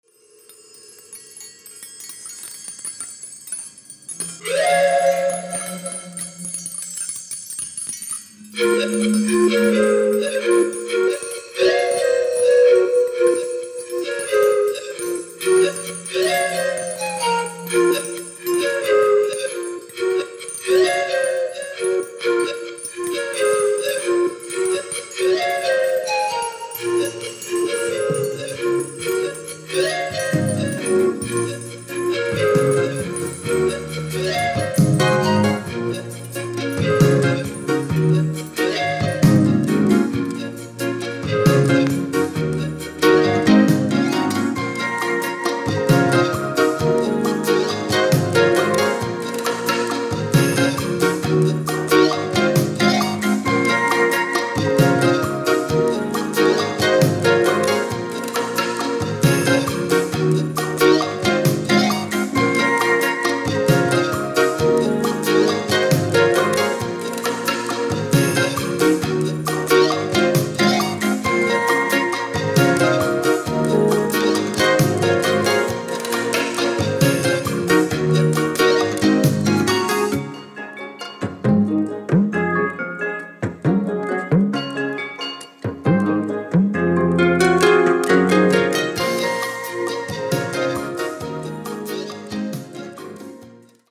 NEW AGEにちょっとFUSIONも混ざっててステキです！！！